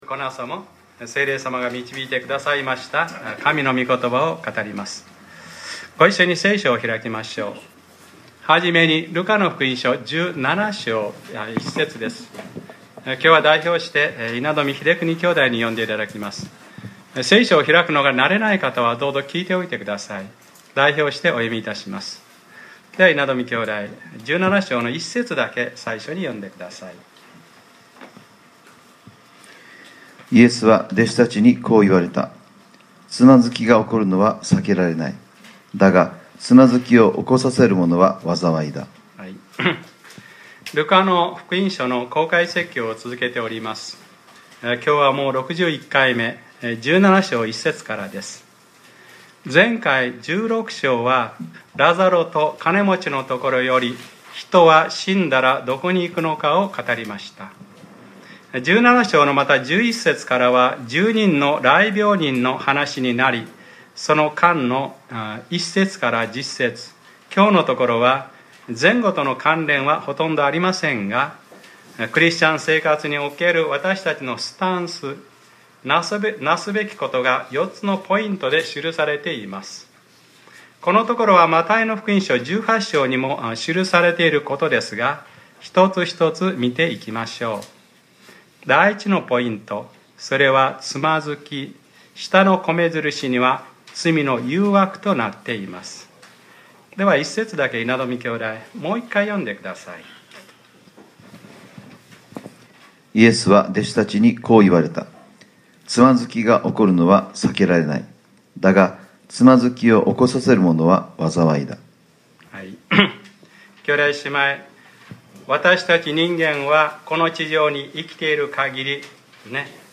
2015年06月07日（日）礼拝説教 『ルカｰ６１：悔い改めれば赦しなさい』